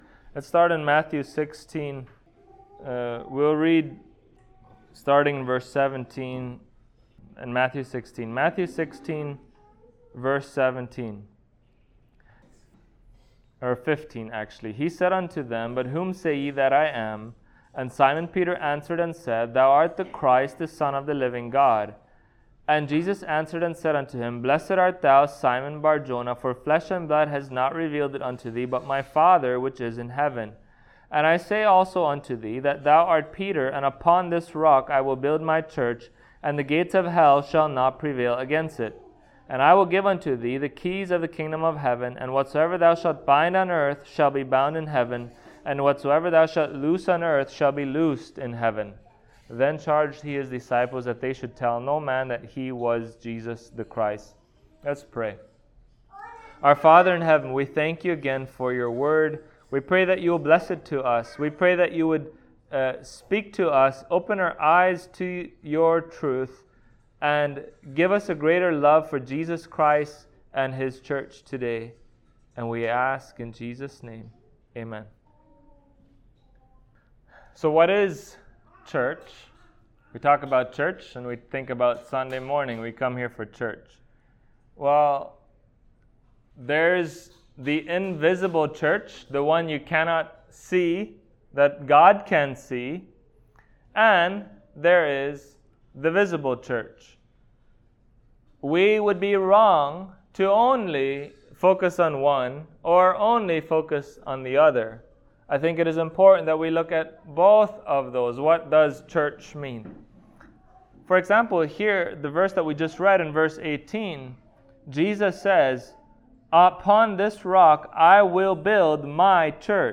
Matthew Passage: Matt 16:18 Service Type: Sunday Morning Topics